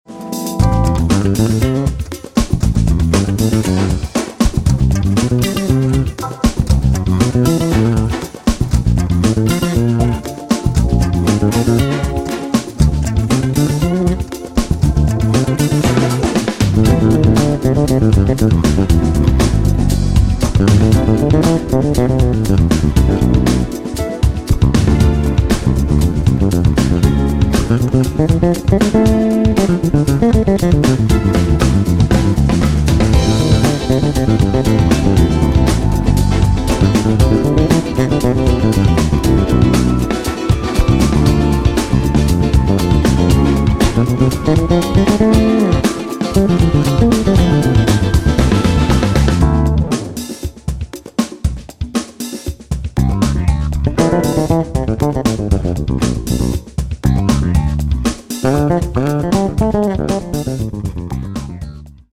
Guitar, Keyboards, Programming
E-Piano, Organ
Drums
Electric Bass, additional Keyboards